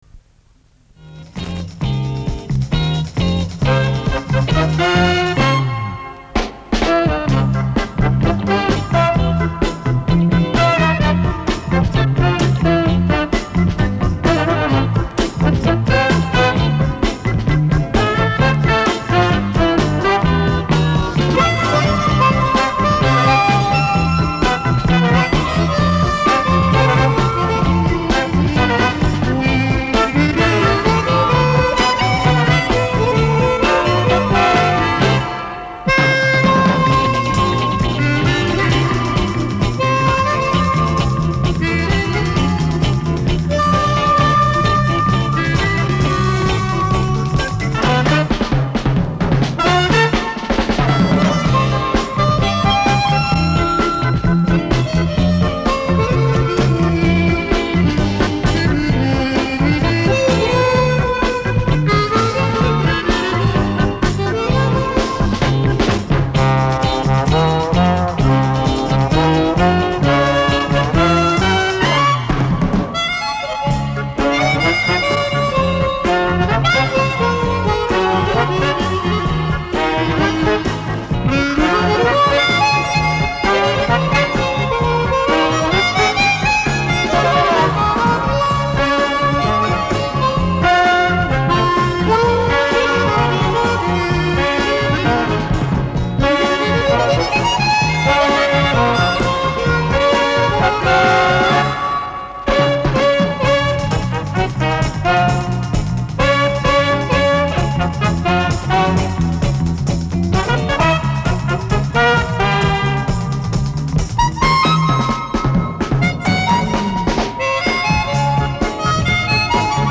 Лет 40 назад записывал с московской радиоточки.